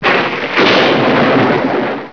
thunder.wav